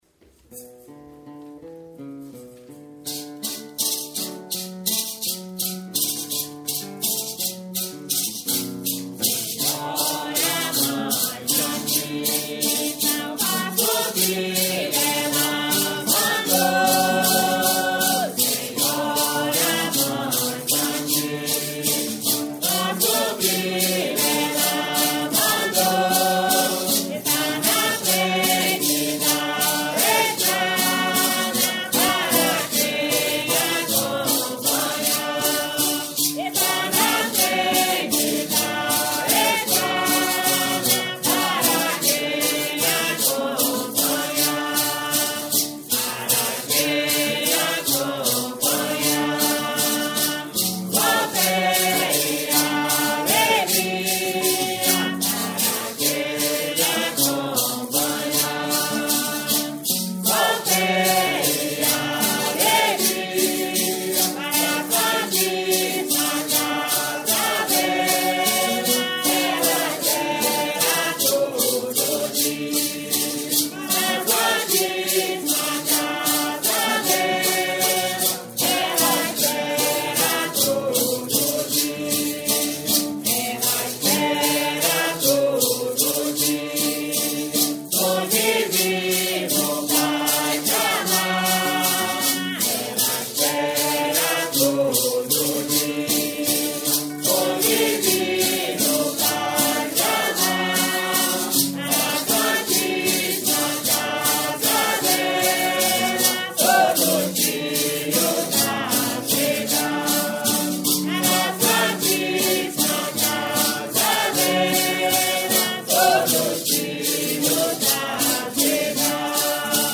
valsa